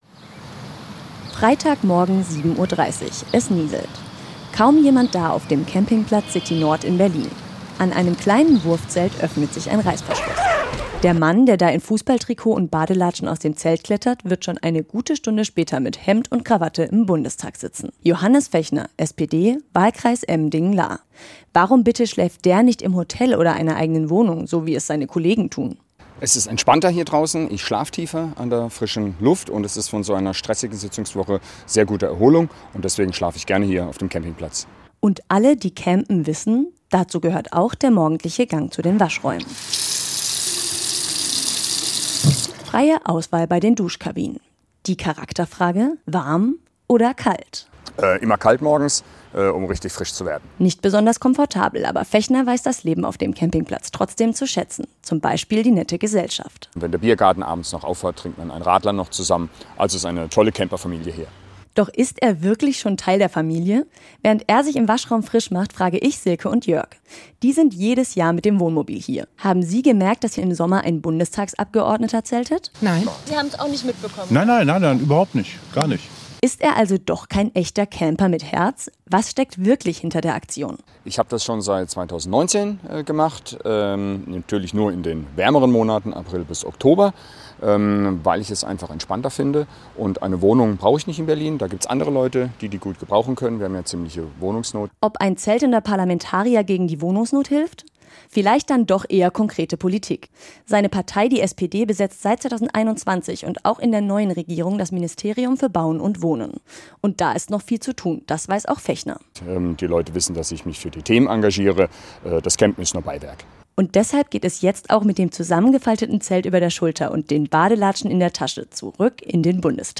hat den Bundestagsabgeordneten auf dem Campingplatz besucht: